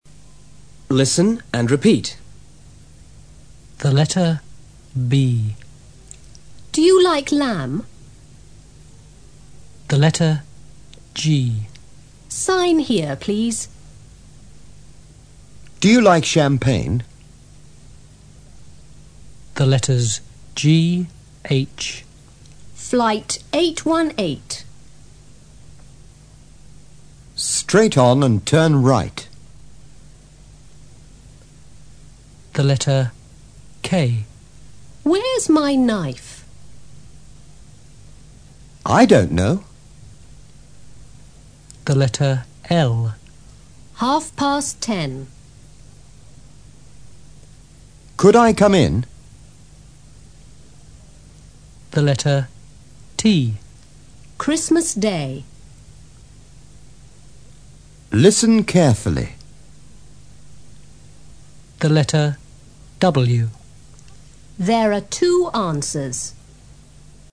En la siguiente práctica de audio podrás oír algunas frases formadas con palabras que contienen consonantes mudas (que no se pronuncian):